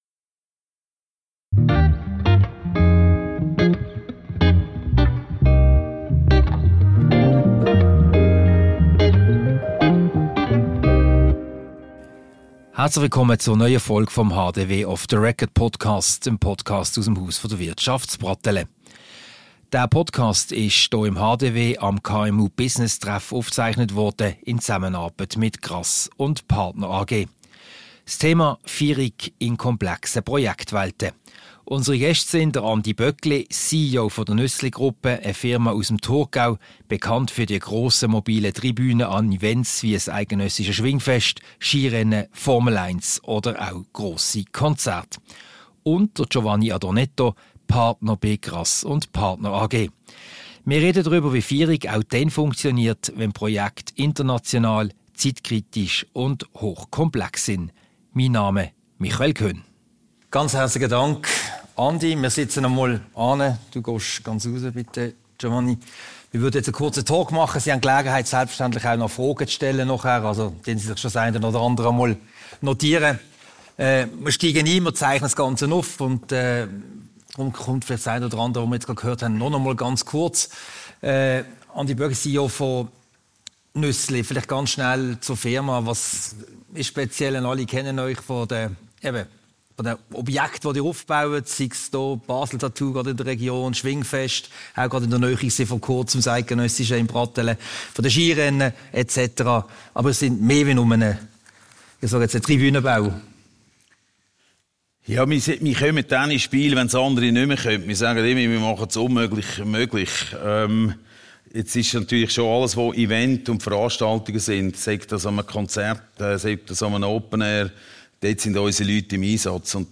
Ein Gespräch über Führung in komplexen Projektwelten wie beispielweise bei Formel-1-Rennen, dem eidgenössischen Schwingfest, der Expo in Osaka oder bei grossen Konzerten wie von Adele.
Diese Podcast-Ausgabe wurde anlässlich des KMU Business Treffs im Haus der Wirtschaft HDW aufgezeichnet, der in Zusammenarbeit mit der Grass und Partner AG stattgefunden hat.